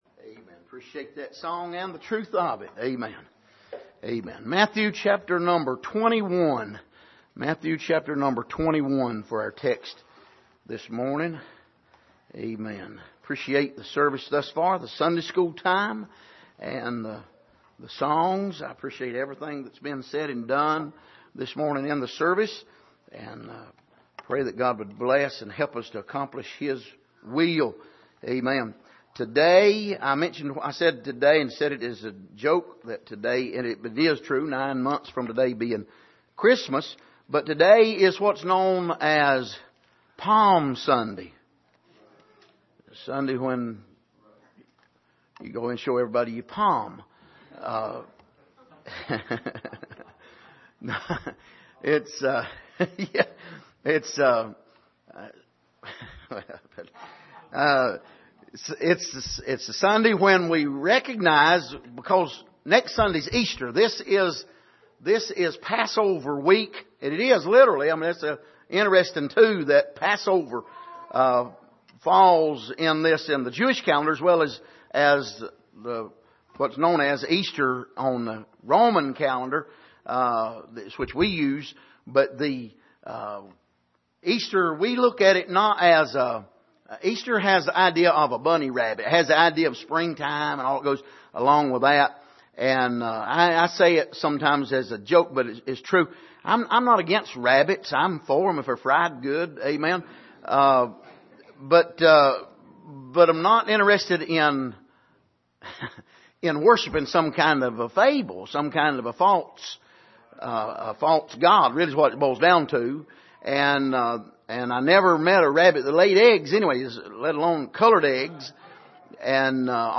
Passage: Matthew 21:1-11 Service: Sunday Morning